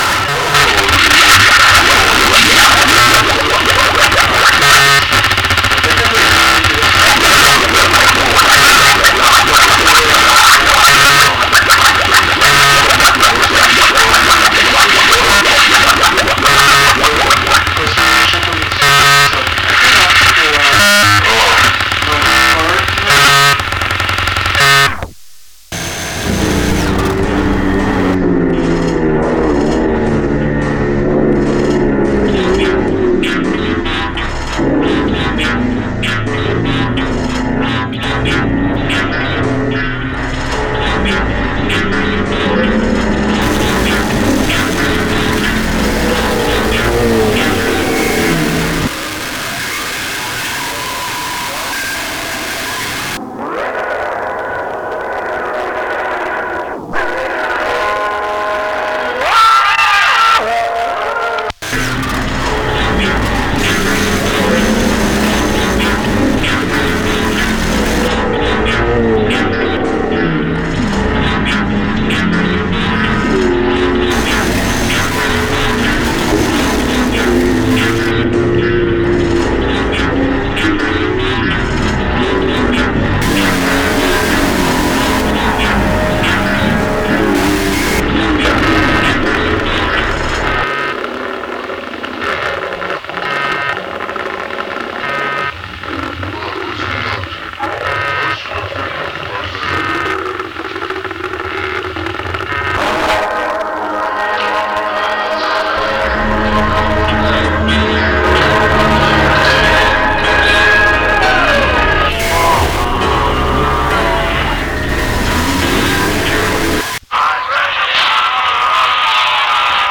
shitcore